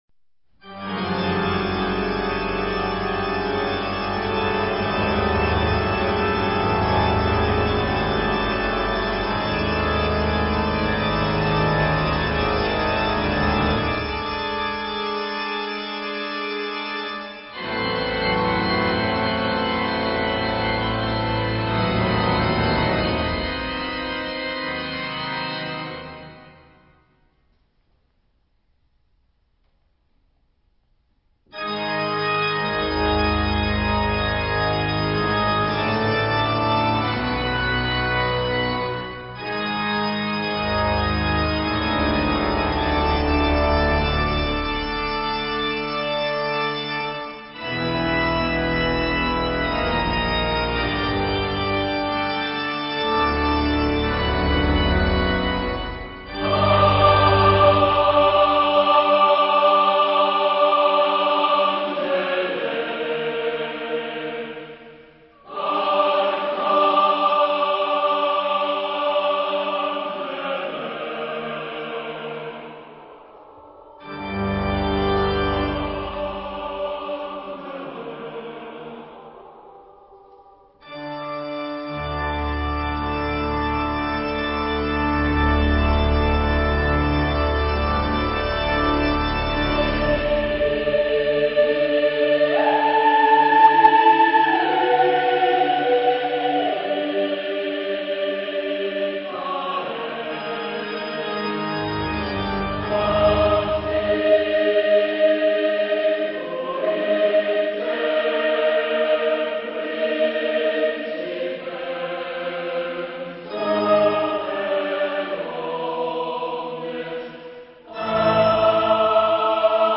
Genre-Style-Forme : Motet ; Sacré
Caractère de la pièce : solennel
Type de choeur : SSSSAAAATB  (10 voix mixtes )
Instruments : Orgue (1)
Tonalité : libre